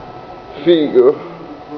click to hear an audio clip means "cool" (as in really neat-o) in Roman dialect.